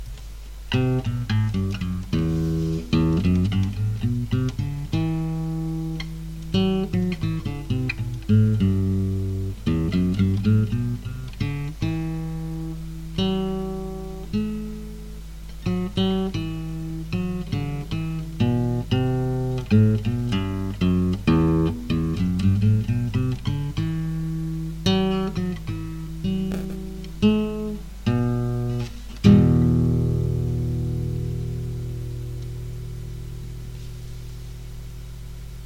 西班牙吉他音符 " Ab2
描述：西班牙吉他的Ab2音符。未经处理。
Tag: 古典 尼龙 尼龙弦 西班牙吉他